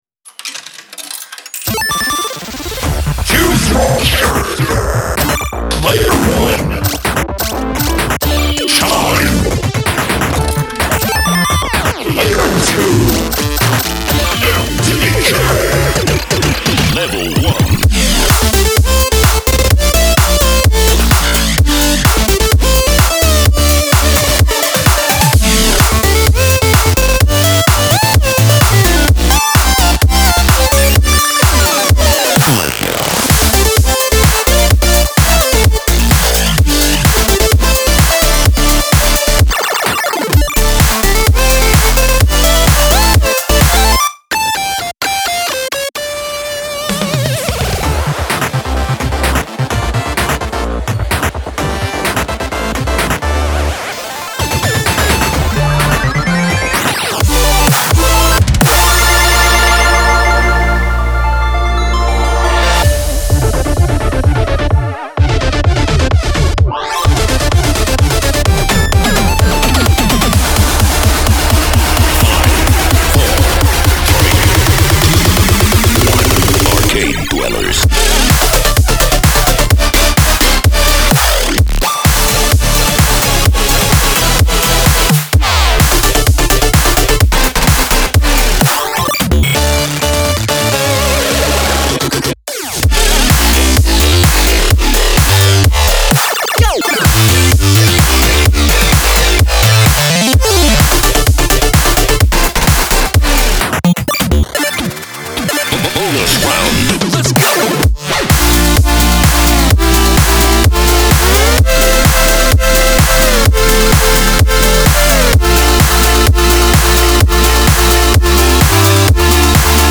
House, Angry, Epic, Energetic, Quirky, Restless